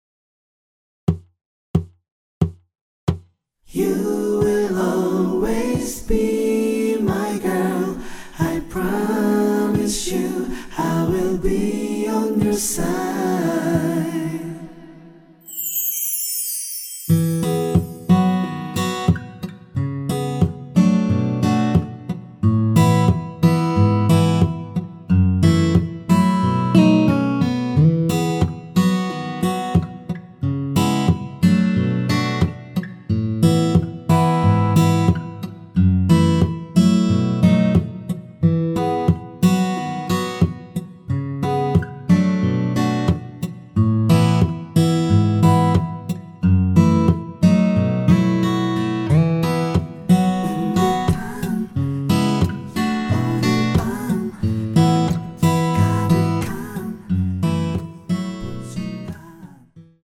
전주 없이 무반주로 노래가 시작 하는 곡이라서
노래 들어가기 쉽게 전주 1마디 넣었습니다.(미리듣기 확인)
원키 코러스 포함된 MR입니다.
앞부분30초, 뒷부분30초씩 편집해서 올려 드리고 있습니다.